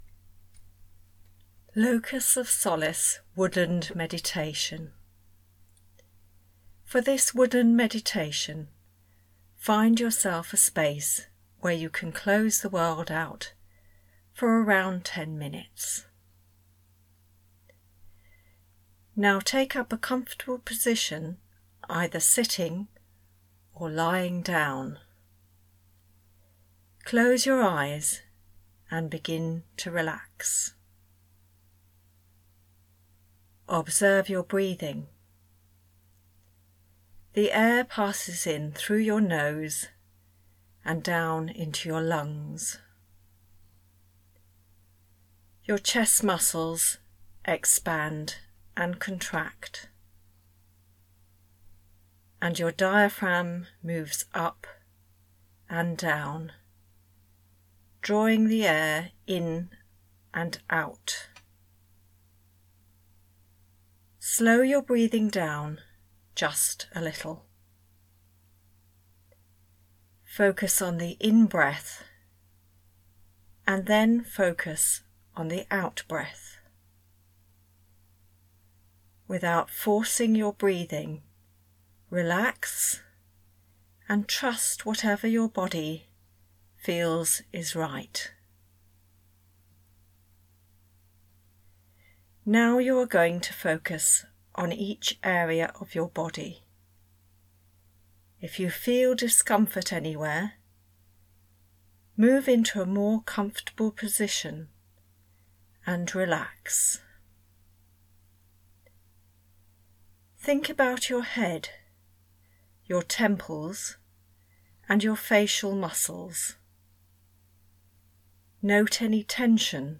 Free download mp3 meditation for personal use